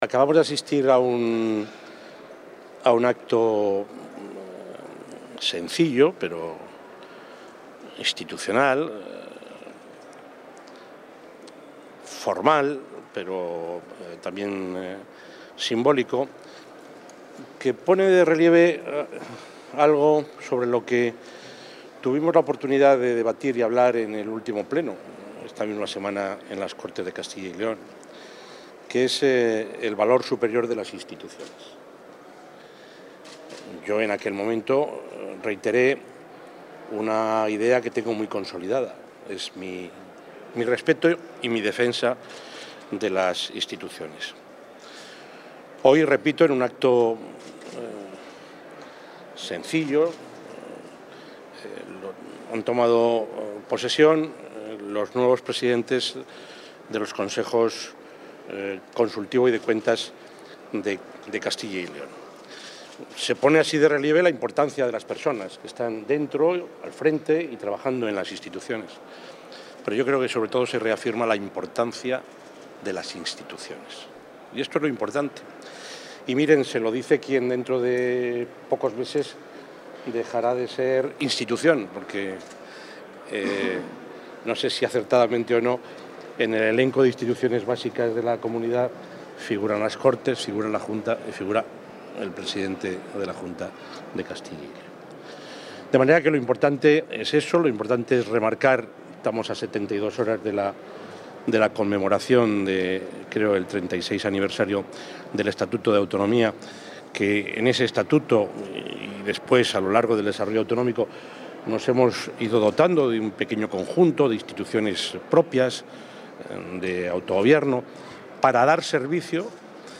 Audio presidente.
El presidente de la Junta de Castilla y León, Juan Vicente Herrera, ha asistido hoy a la toma de posesión de los presidentes del Consejo Consultivo y del Consejo de Cuentas de Castilla y León.